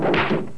Mr. T punching someone.
Mrtpunch.wav